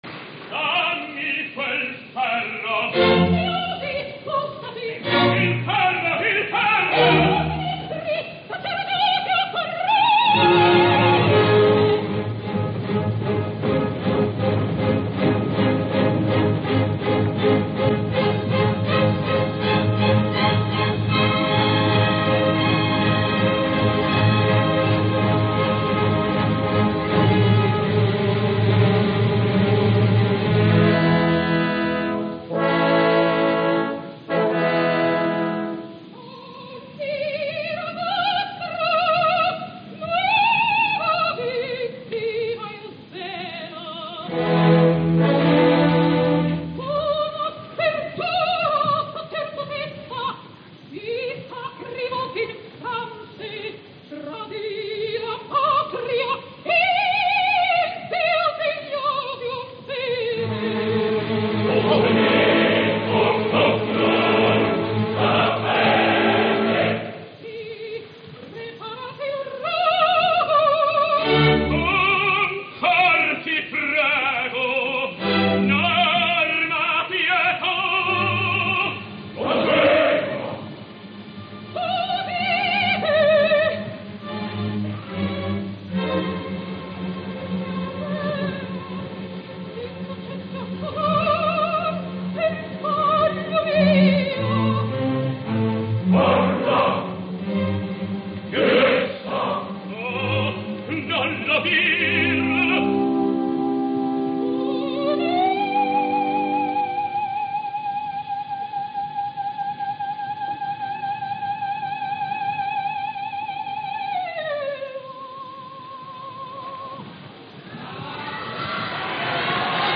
Performance of December 7th, 1955
at Teatro alla Scala, Milano
Live performance